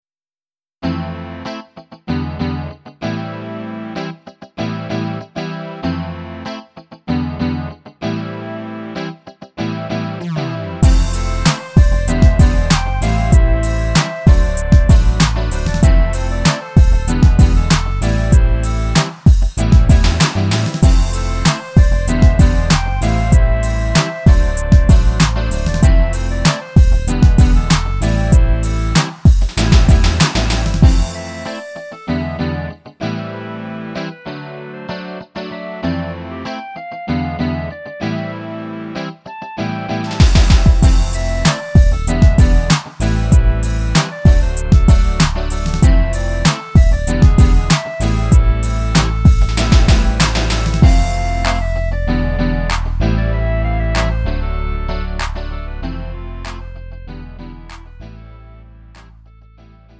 음정 -1키 3:17
장르 구분 Lite MR